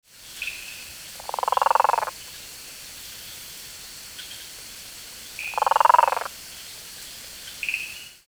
Play Especie: Mantidactylus guttulatus Género: Manidactylus Familia: Mantellidae Órden: Anura Clase: Amphibia Título: The calls of the frogs of Madagascar.
Localidad: Madagascar: Campamento Antsahamanara, bosque Manarikoba, Reserva Naural Estricta Tsaratanana
Tipo de vocalización: Llamada
96 Mantidactylus guttulatus.mp3